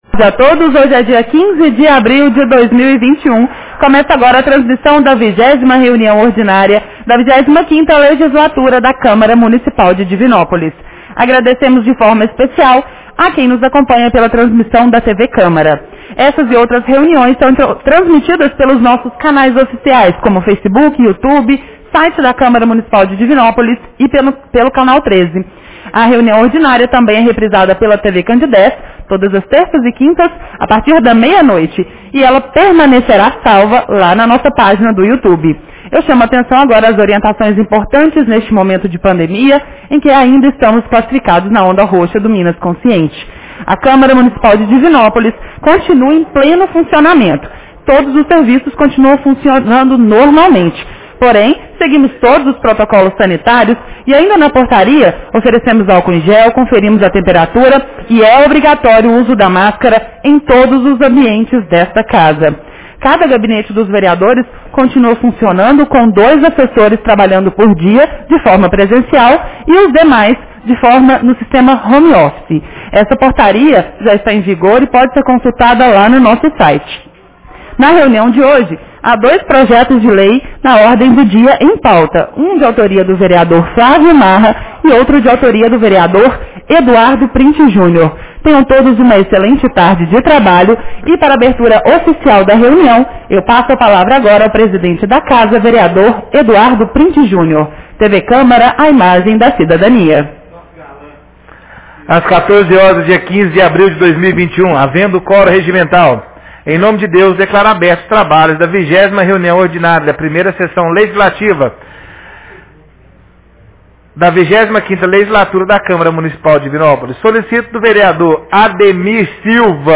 Reunião Ordinária 20 de 15 de abril 2021